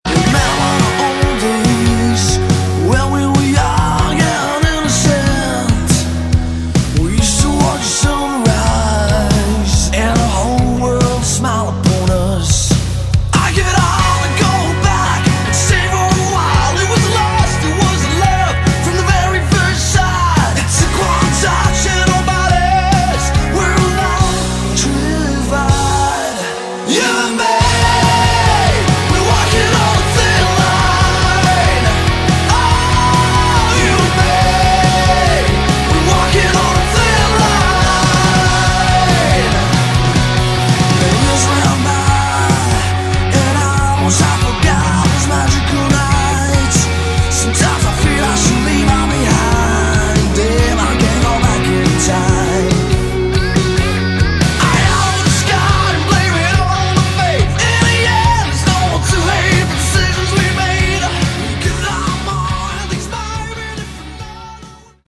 Category: Hard Rock
I like the more dangerous, youth gone wild sleazy edge.